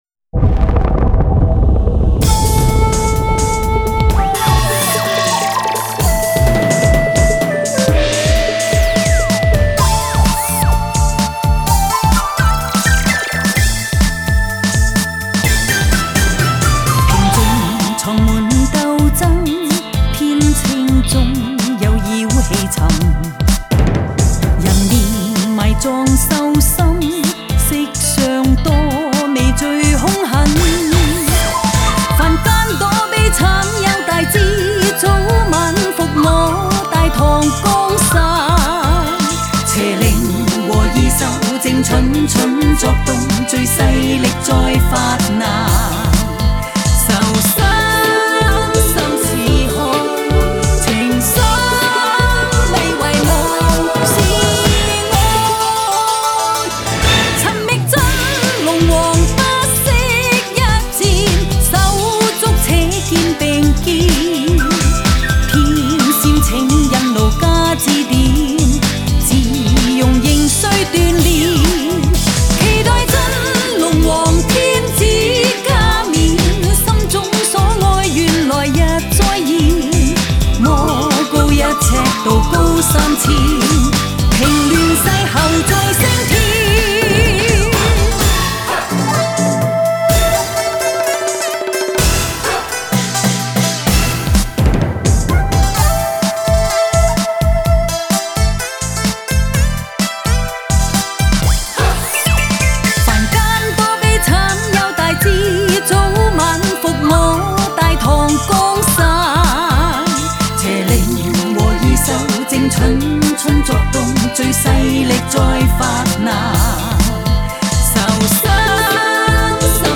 Ps：在线试听为压缩音质节选，体验无损音质请下载完整版 世间看似平静，实则暗藏诸多事端。